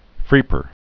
(frēpər)